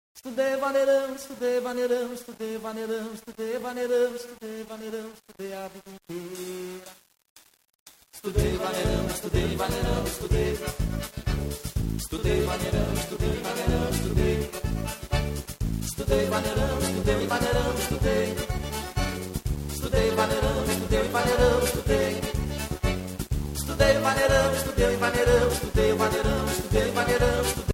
berimbau
accordion